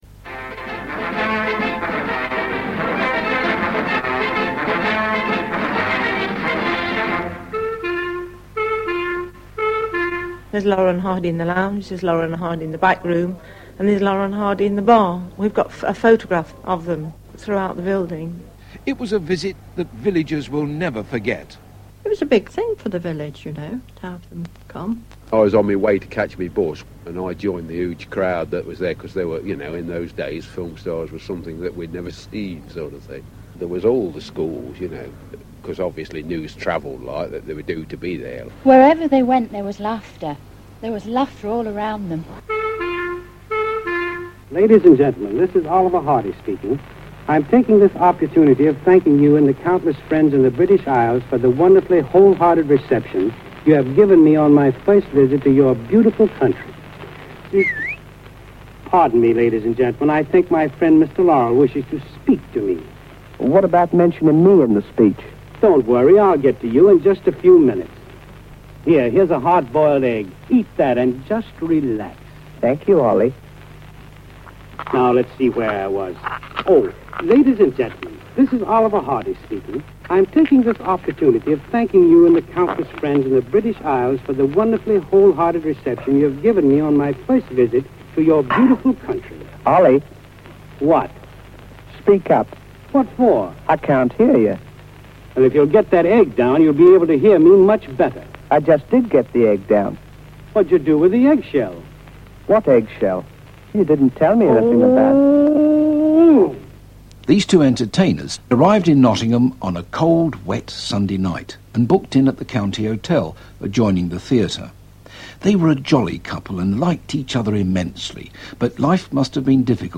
In 1952/53 Laurel and Hardy made a tour of Europe. Whilst performing in Nottingham they stopped off at the Bull Inn at Bottesford, a pub run by Stan’s sister Olga. In this report